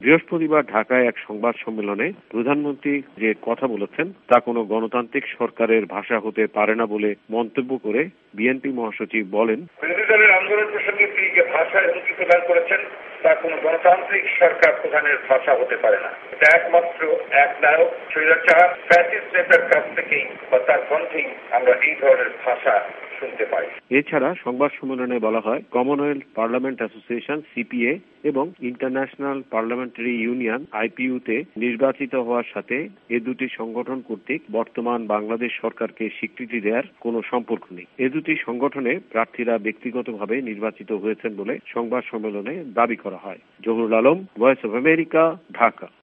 টেলিফোন বার্তা: